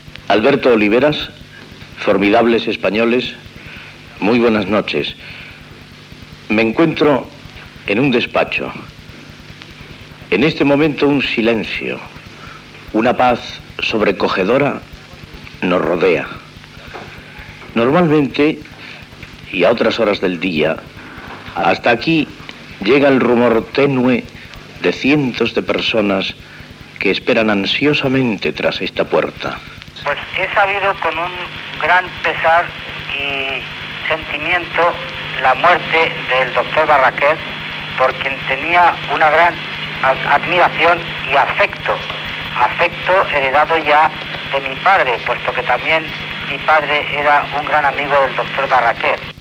Mort del doctor oftalmòleg Ignacio Barraquer. Connexió amb el seu despatx buit de la Clínica Barraquer de Barcelona i paraules de reconeixement de l'oftalmòleg Ramón Castroviejo.
Fragment extret d'"Historia de Radio Barcelona 1924-1974" (1974).